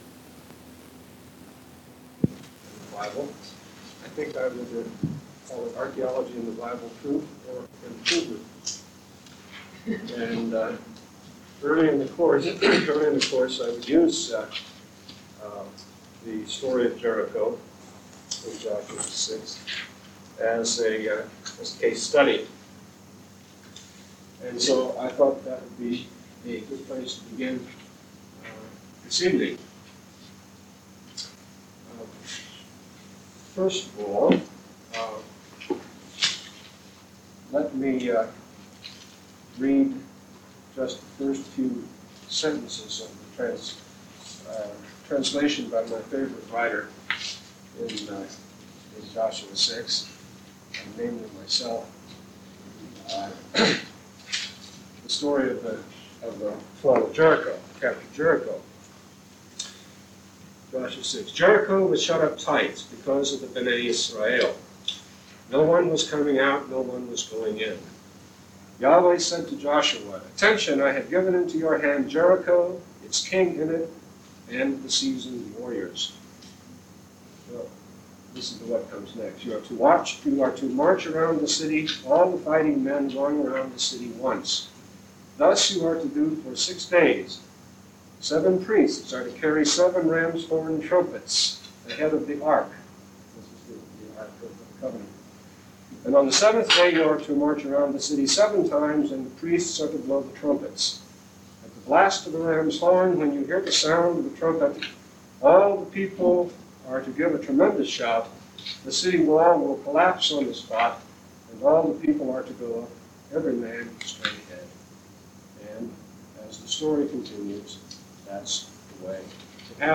Archaeology and the Book of Joshua and Judges, Lecture #2